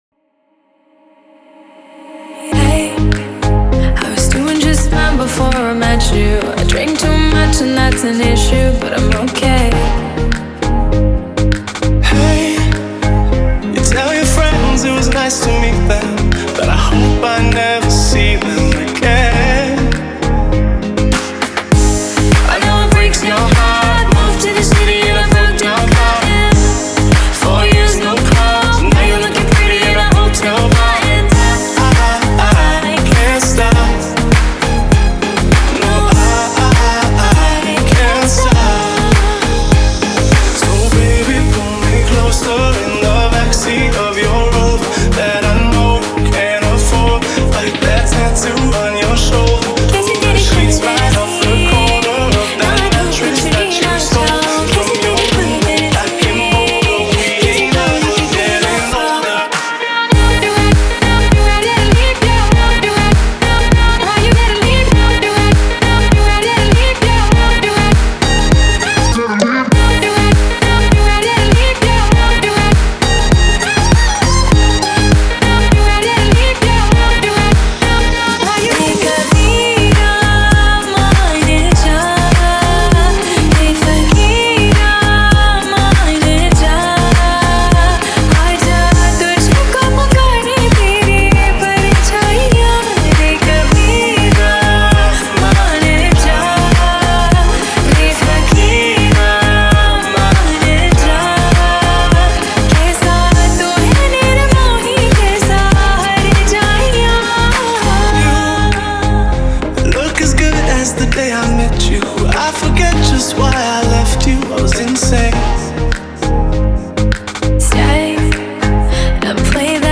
DJ Remix Mp3 Songs